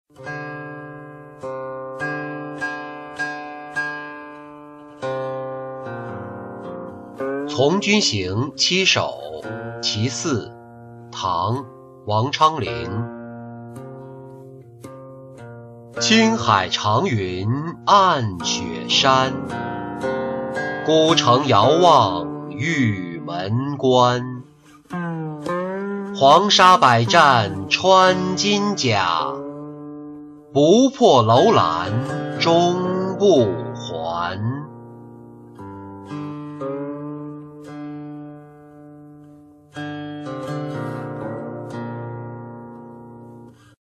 从军行七首·其四-音频朗读